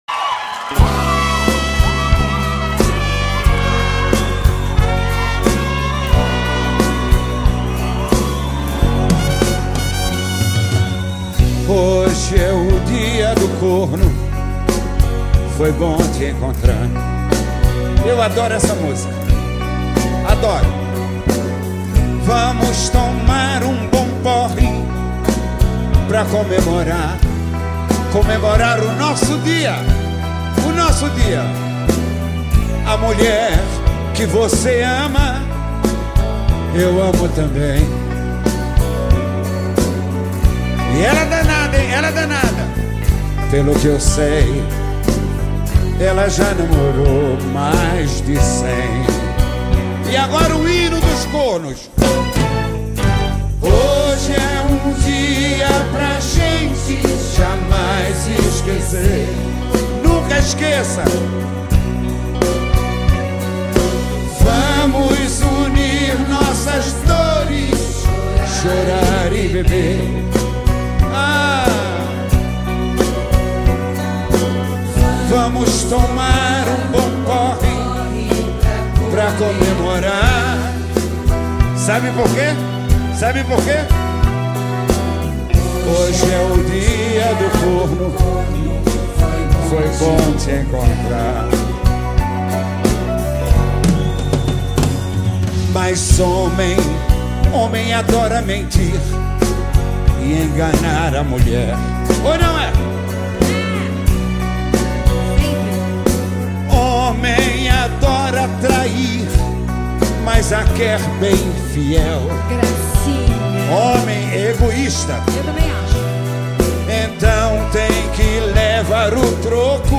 A PAUTA DO BAÚ DA NOSSA MÚSICA  TEM MAIS UM BREGA RASGADO